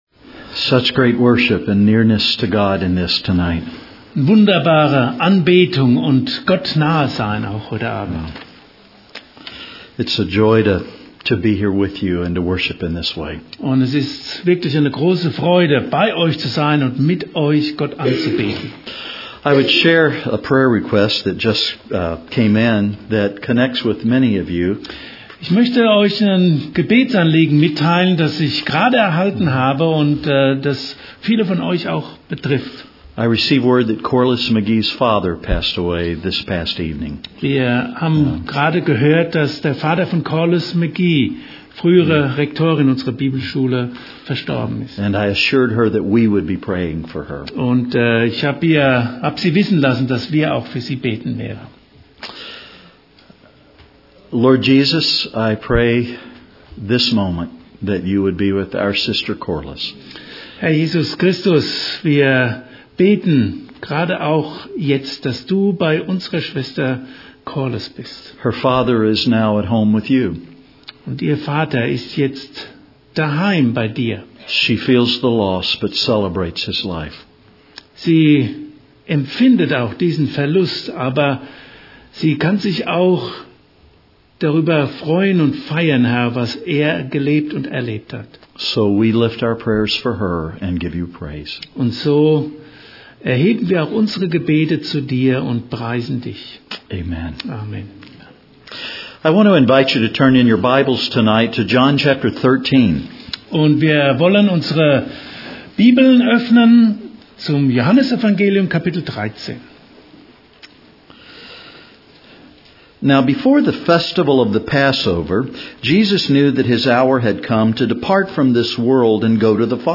EuNC LC 2013 - Tuesday, 29 January 2013 - Evening Service
European Nazarene College Leadership Conference 2013, Tuesday Evening Service